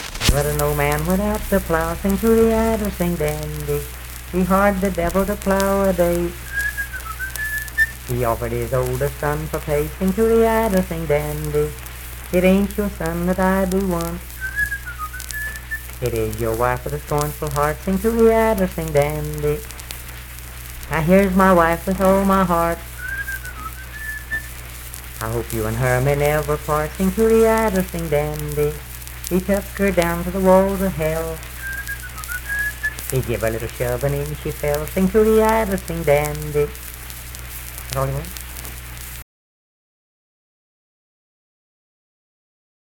Unaccompanied vocal music performance
Verse-refrain 5(4w/R).
Voice (sung)